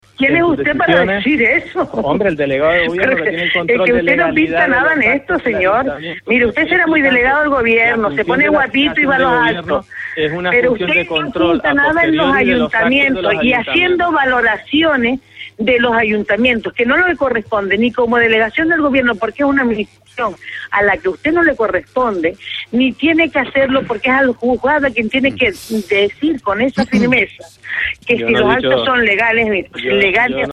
Claudina Morales y Hernández Bento protagonizan una acalorada bronca tras la censura frustrada en La Oliva
La cabeza de lista de Coalición Canaria (CC) en el Ayuntamiento de La Oliva, en Fuerteventura, y el delegado del Gobierno en Canarias, Enrique Hernández Bento, han protagonizado este martes en Cadena Cope una acalorada discusión política a cuenta de la censura frustrada finalmente el pasado viernes en el Ayuntamiento majorero contra el actual alcalde, Pedro Amador.
bronca_claudina_-_hdz_bento_1.mp3